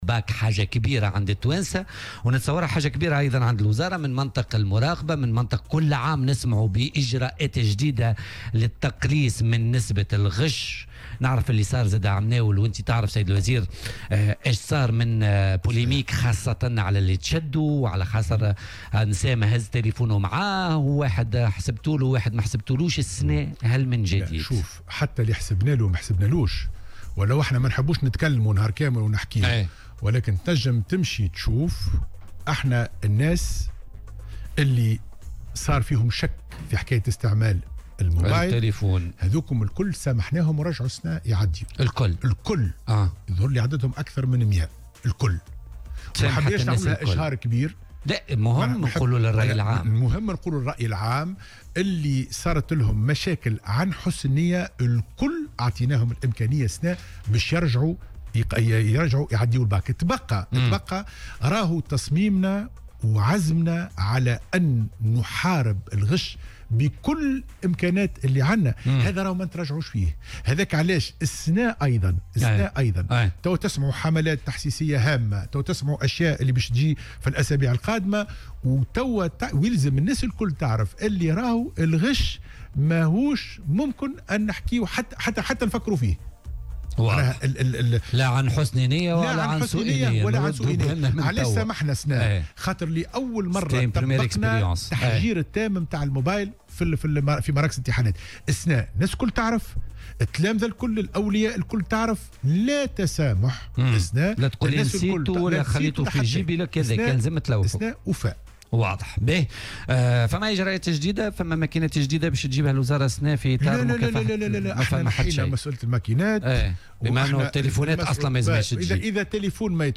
أكد وزير التربية حاتم بن سالم ضيف بولتيكا اليوم الثلاثاء 19 مارس 2019 أن الوزارة تسامحت مع مرتكبي عمليات الغش في الباكالوريا العام الماضي ممن اتخذت في شأنهم قرارات عقابية.